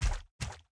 foot_1.wav